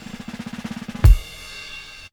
06DR.BREAK.wav